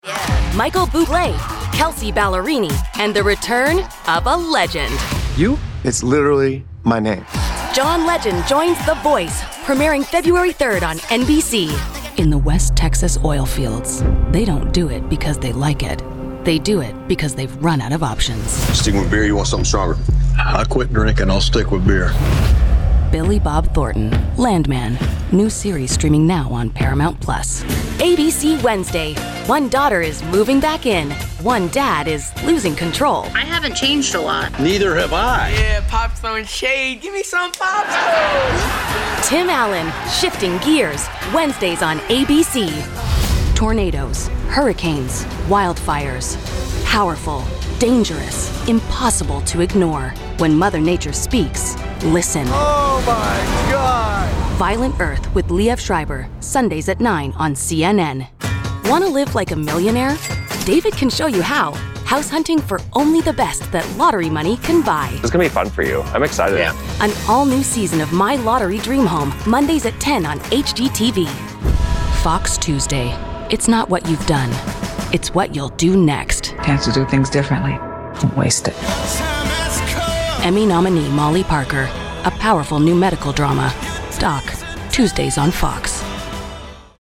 Female
My voice is warm, direct, and conversational, with an authentic and clear delivery.
Main Demo
Bright, Conversational, Real